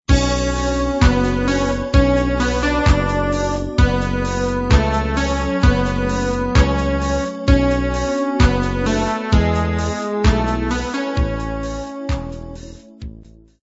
Заказ полифонической версии:
• Пример мелодии содержит искажения (писк).